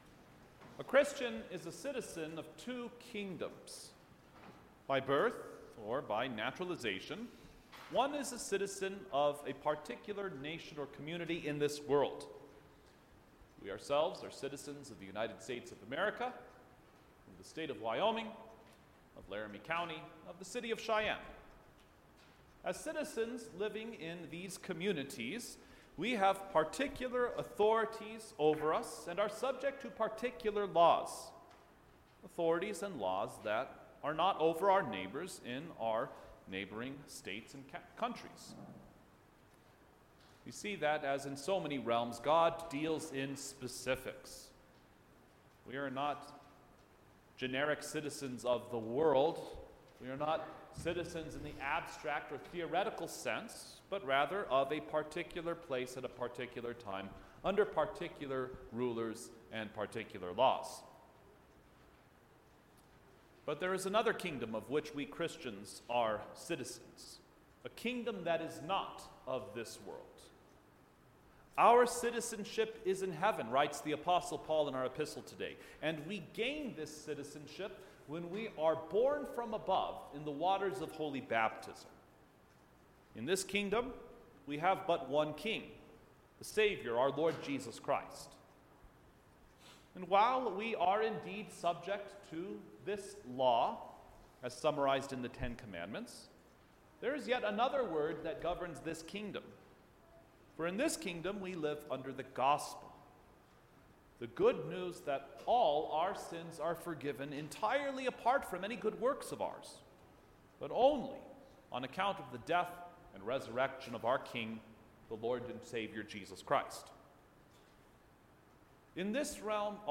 November-15-Twenty-Third-Sunday-After-Trinity_sermon.mp3